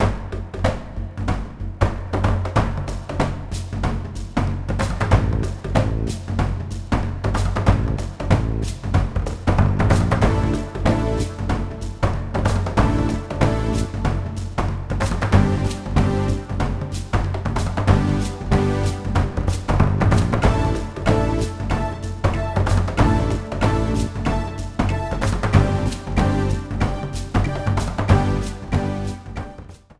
30 seconds trim, fadeout